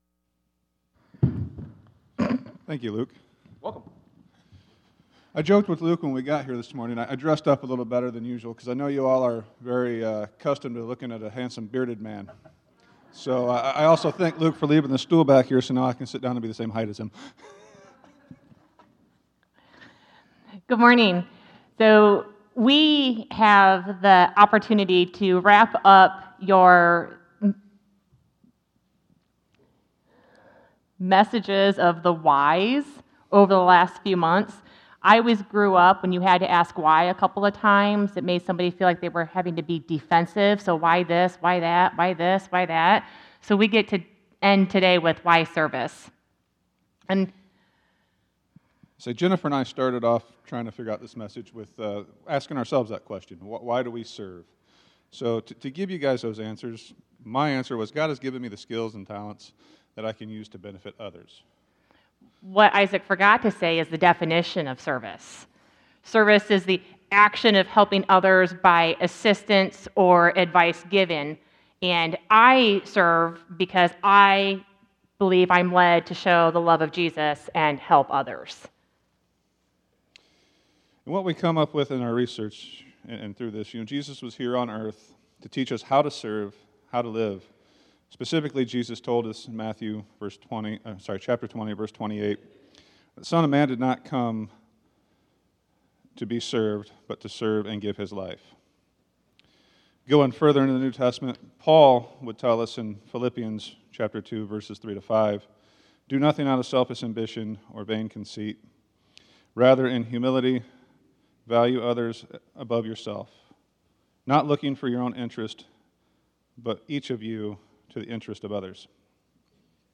Sermons Archive